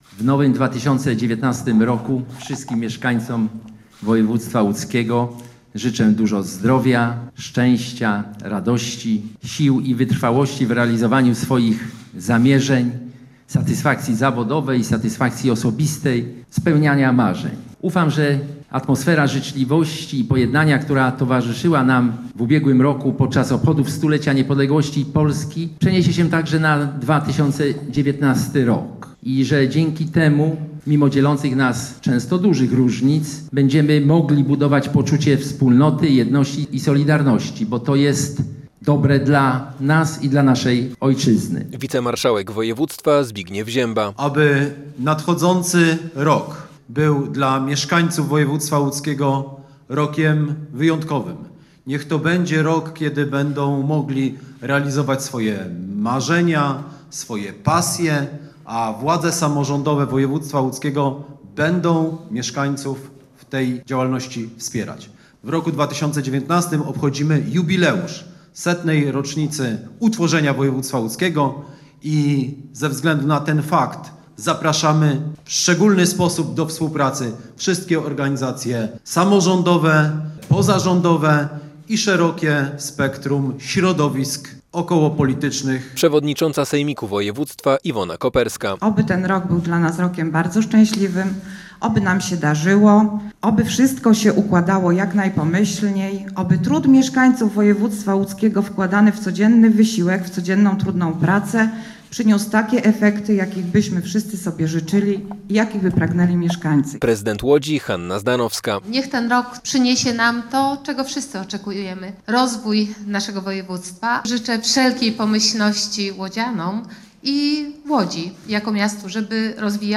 W tradycyjnym spotkaniu w Urzędzie Wojewódzkim wzięli udział także parlamentarzyści, przedstawiciele samorządów, służ mundurowych i uczelni. Jako pierwszy noworoczne życzenia mieszkańcom regionu złożył wicewojewoda Karol Młynarczyk.